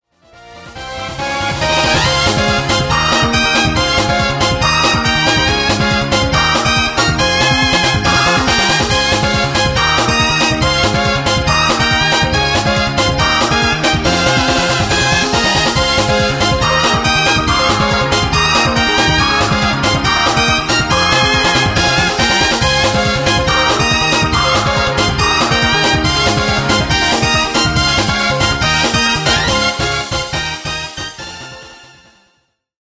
東方風自作曲
妖精らしい感じに作ってみました。